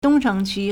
东城区 (東城區) dōngchéng qū
dong1cheng2qu1.mp3